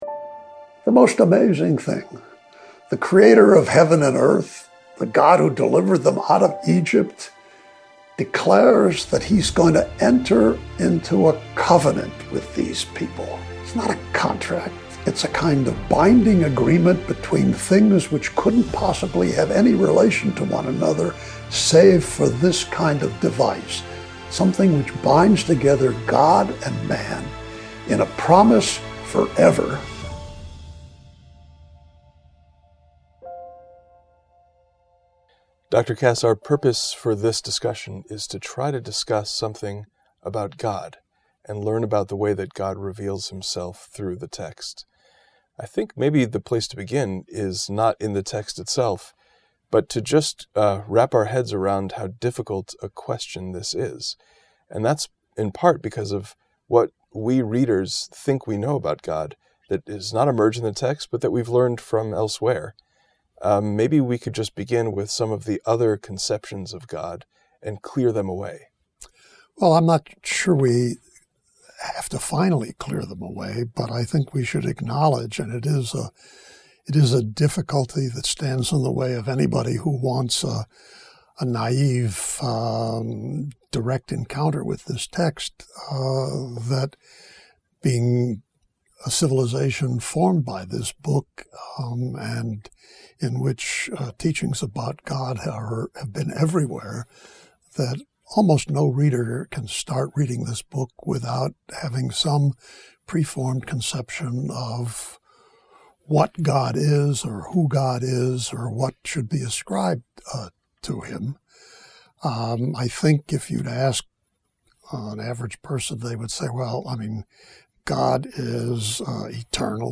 In this conversation, Professor Kass challenges us to set those preconceptions aside and think about God as He is presented...